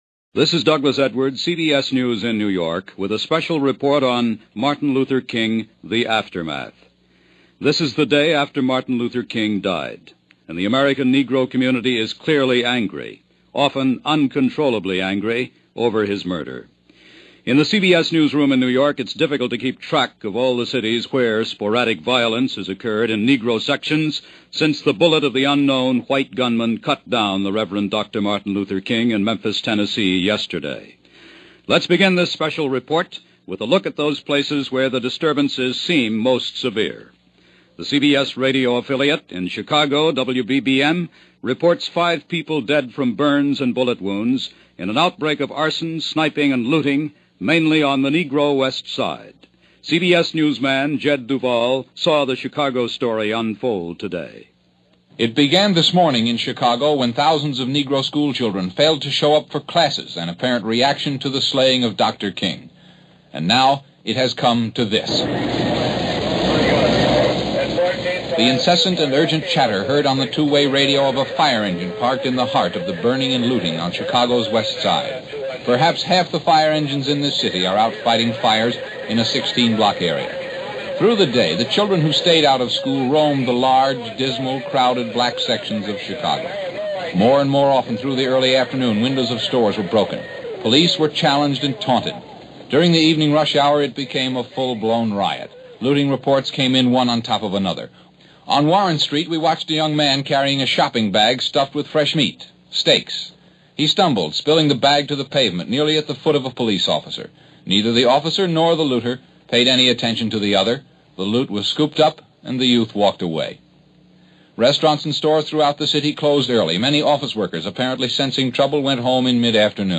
April 5, 1968 – Dr. Martin Luther King Assassination – The Day After – CBS Radio – Gordon Skene Sound Collection –
CBS-Radio-Martin-Luther-King-The-Aftermath.mp3